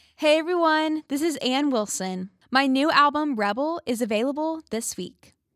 LINER Anne Wilson (available this week)
CUT-3-LINER-Anne-Wilson-available-this-week.mp3